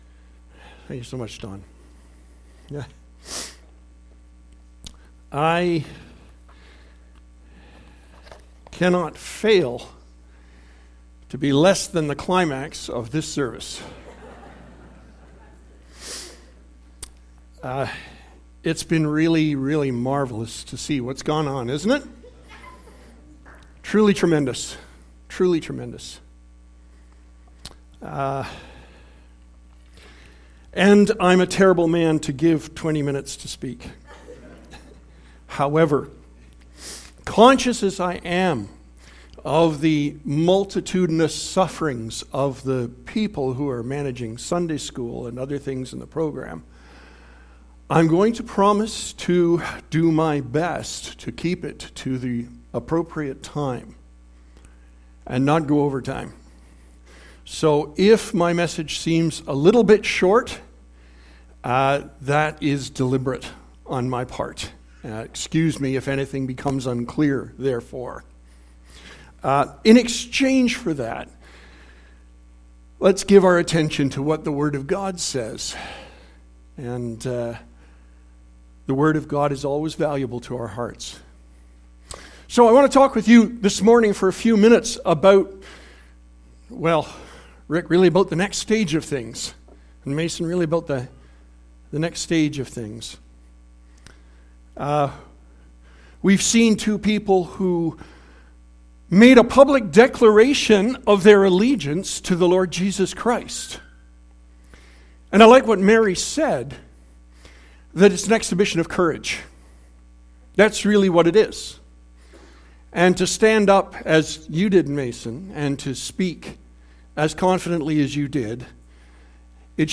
Sermon Audio and Video